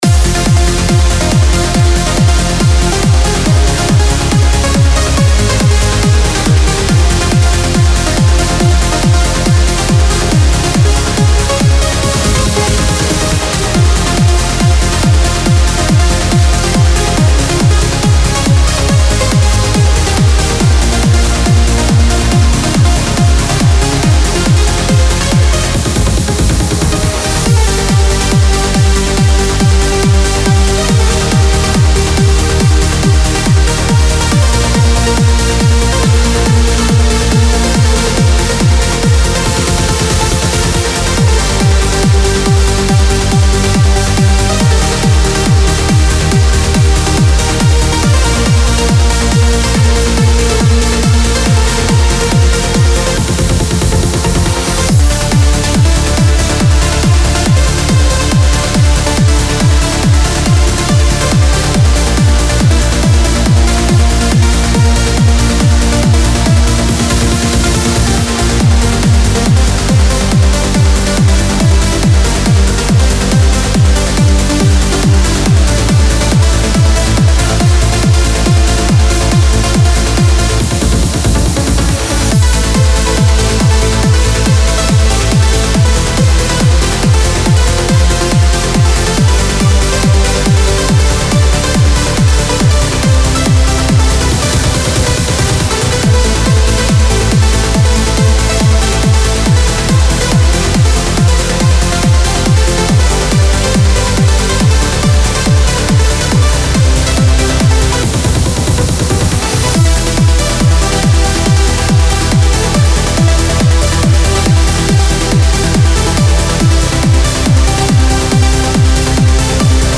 Trance Uplifting Trance
(Preview demo is 140 BPM)
Style: Trance, Uplifting Trance